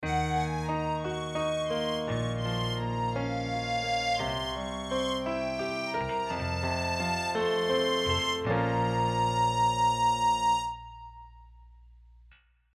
Plays long end of the track